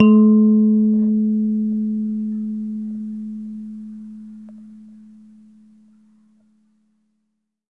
姆比拉
描述：我在肯尼亚的拇指钢琴上做了一个小小的重复模式。 B小调五声音。记录为H5。
标签： 拇指钢琴 非洲 固定音型 节奏
声道立体声